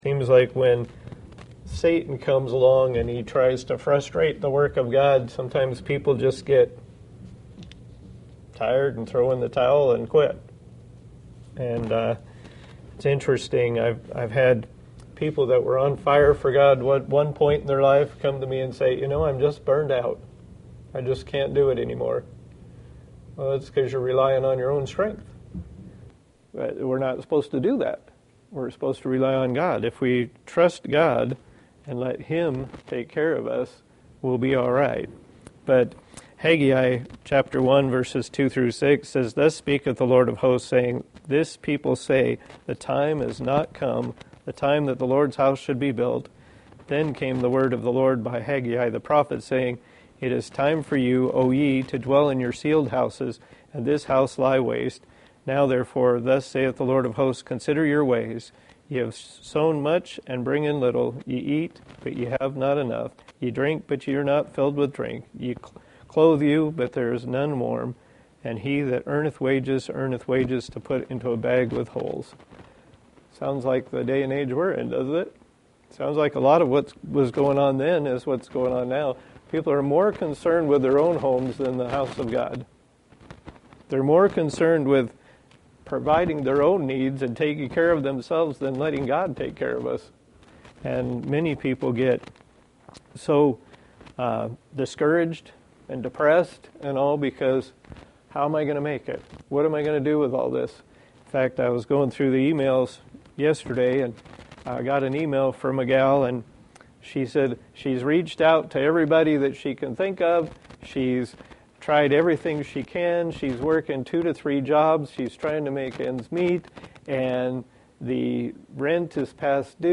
Ezra 4:5-14 Service Type: Sunday School « Colossians Introduction